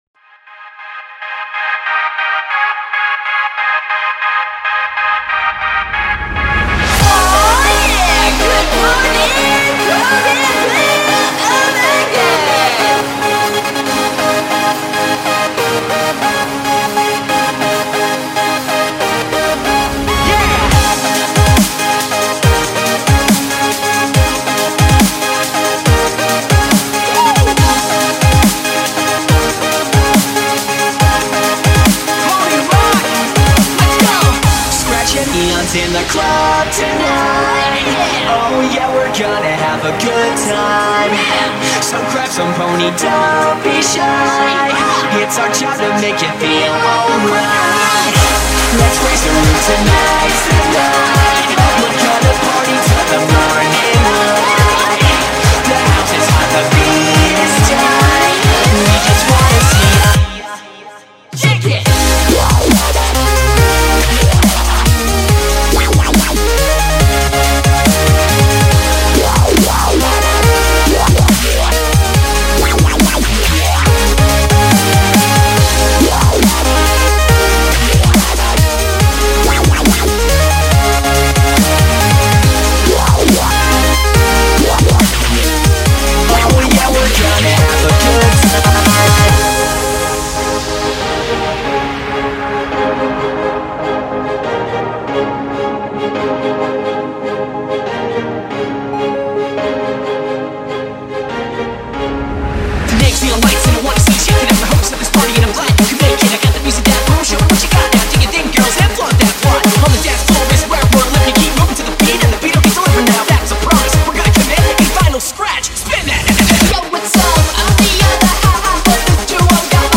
genre:remix
genre:rock